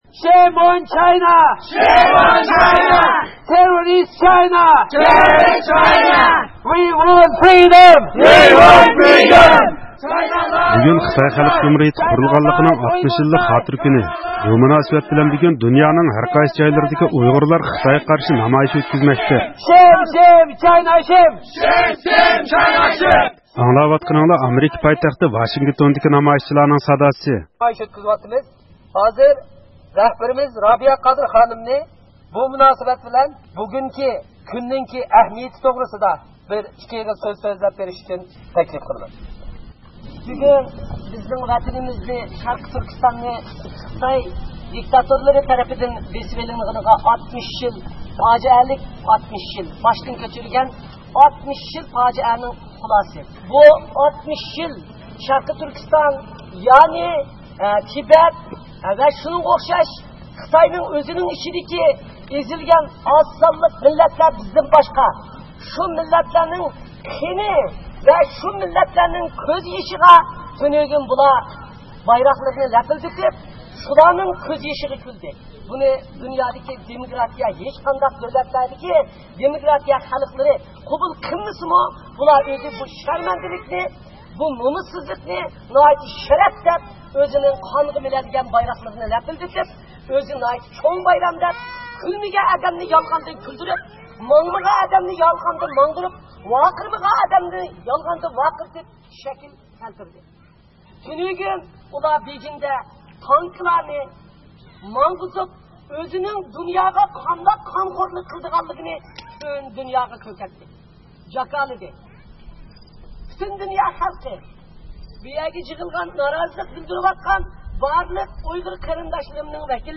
ئامېرىكا پايتەختى ۋاشىنگتوندىكى بۈگۈنكى بۇ نامايىشقا، ۋاشىنگىتون ۋە ۋاشىنگىتون ئەتراپىدىكى ئۇيغۇرلار قاتناشتى. نامايىشچىلار » قىرغىنچىلىققا خاتىمە بېرىلسۇن!» «تۈرمىلەردىكى قىيىن – قىستاق توختىتىلسۇن!» «خىتاي شەرقى تۈركىستاندىن چىقىپ كەتسۇن!» قاتارلىق شوئارلارنى توۋلىدى.